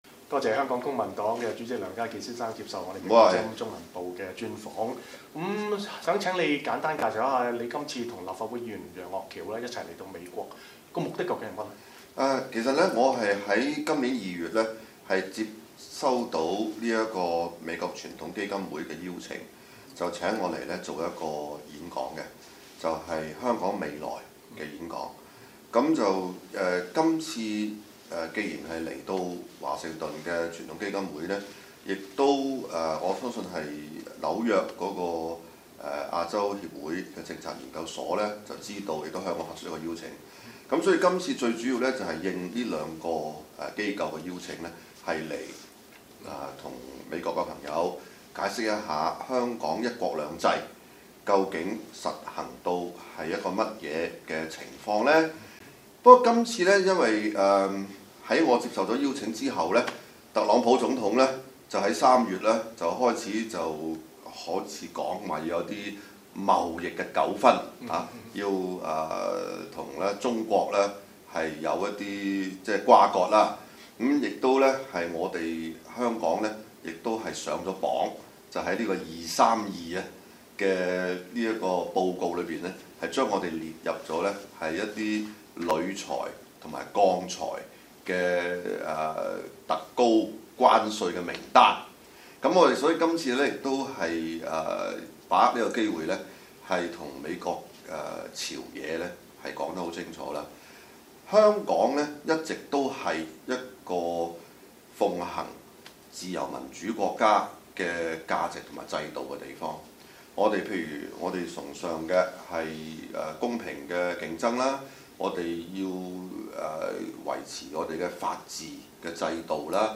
美國之音獨家專訪香港公民黨主席梁家傑